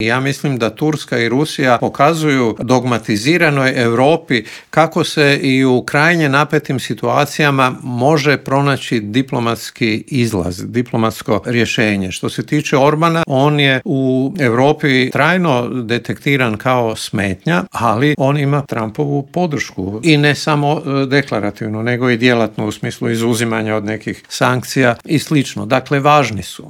Na ta i ostala povezana pitanja u intervjuu Media servisa odgovorio je vanjskopolitički analitičar i bivši ambasador Hrvatske u Moskvi Božo Kovačević.